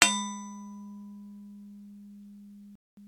bell ding percussion spackle-knife sound effect free sound royalty free Sound Effects